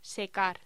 Locución: Secar
voz
Sonidos: Voz humana